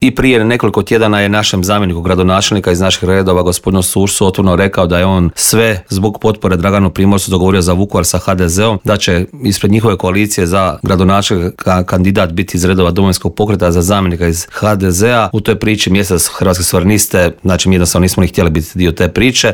O ovogodišnjoj obljetnici i brojnim drugim političkim aktualnostima u Intervjuu Media servisa razgovarali smo s predsjednikom Hrvatskih suverenista Marijanom Pavličekom koji je poručio: "Čovjek se naježi kada vidi sve te mlade ljude koji idu prema Vukovaru."